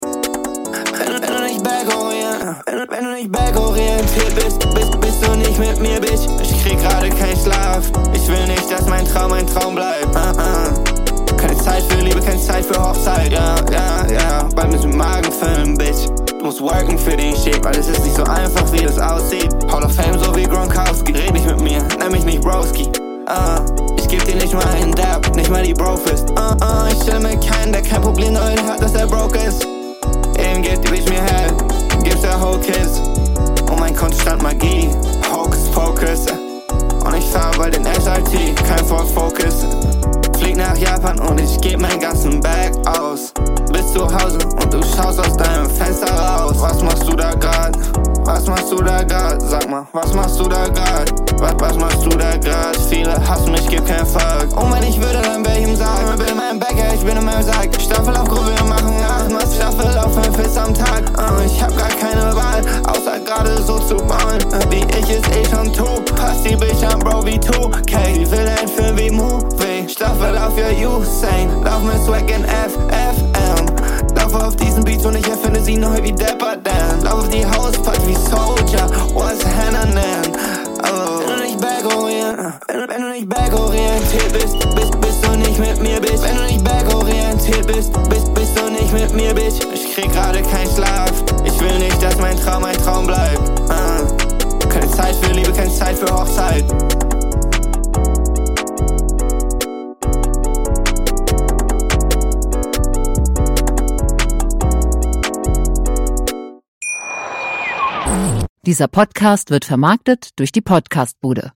KEINE ADLIBS